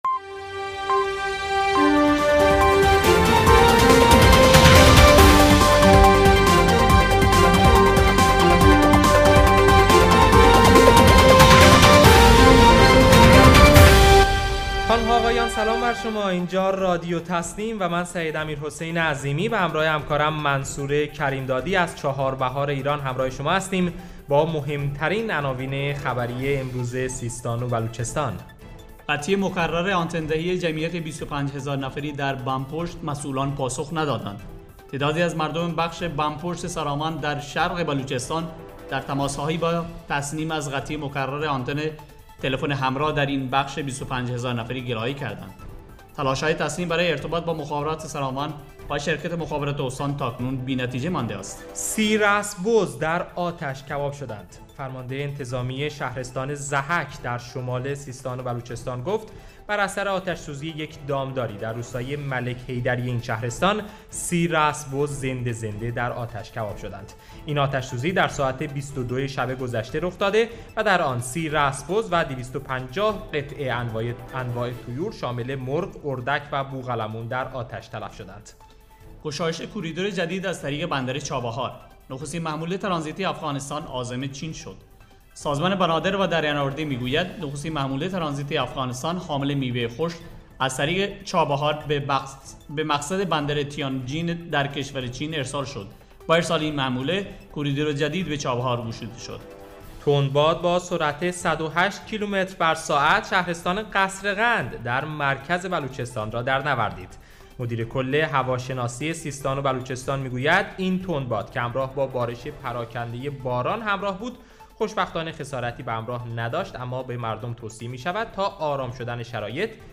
گروه استان‌ها ـ در دومین بسته خبری رادیو تسنیم سیستان و بلوچستان با مهم‌ترین عناوین خبری امروز همراه ما باشید.